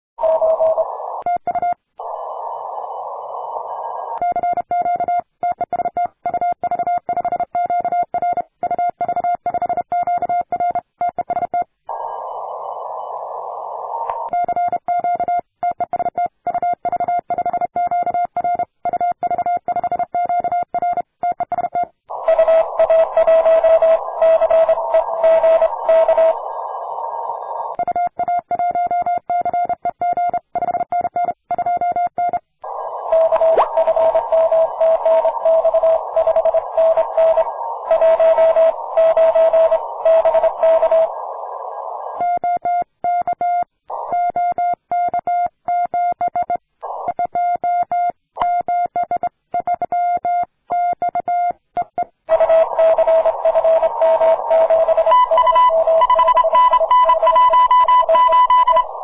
На 15 метров, в лучшем виде - прекрасный сигнал!